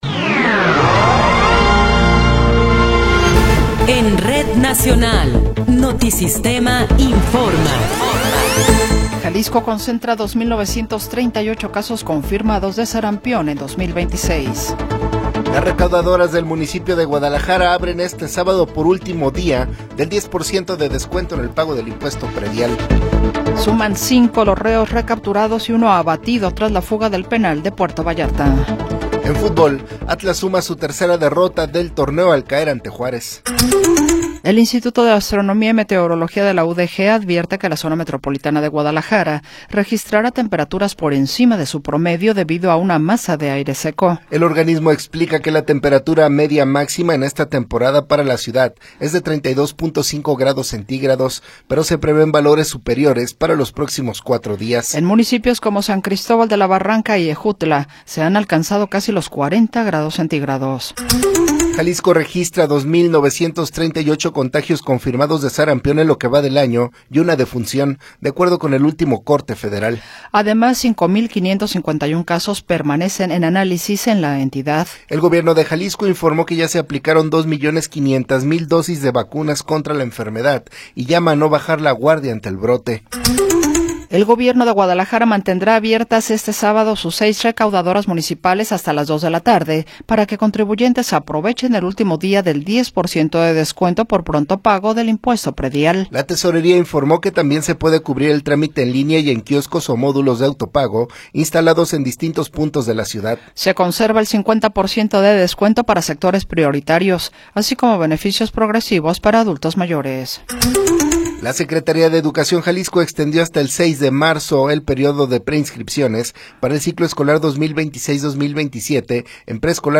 Noticiero 9 hrs. – 28 de Febrero de 2026
Resumen informativo Notisistema, la mejor y más completa información cada hora en la hora.